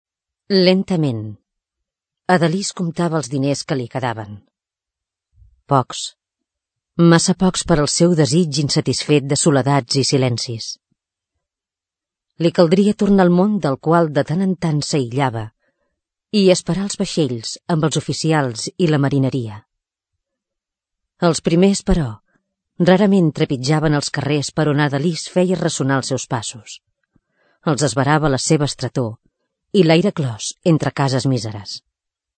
E ls e sv e rav a l a sev a e str e tor i l’air e clos entr e cas e s mís e r e s. Mercè Rodoreda , Ada Liz Si vols pots sentir el text per observar el so de la vocal neutra. text oral